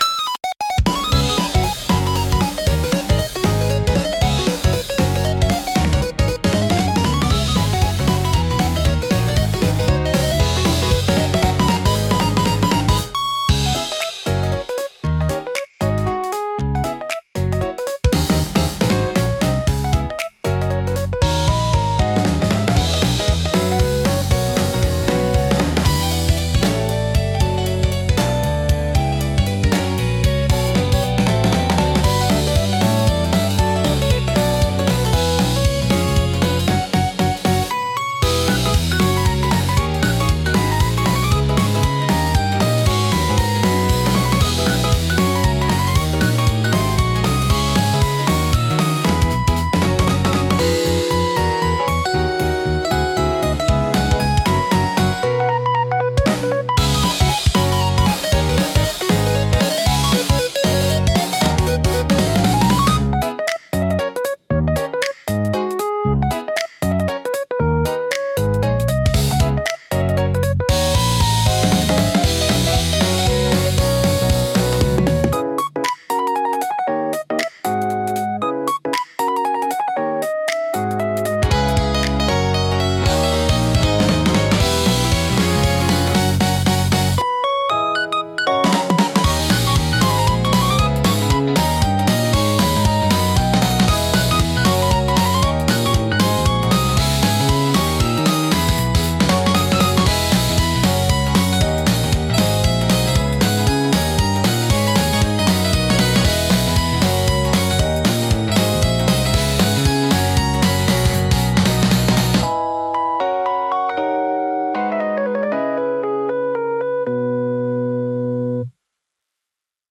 元気で前向きな印象を与え、若年層やファミリー向けコンテンツに特によく合います。